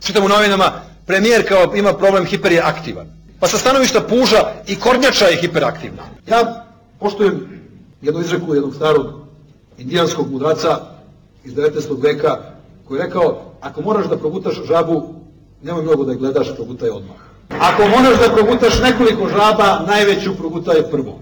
Govor Đinđića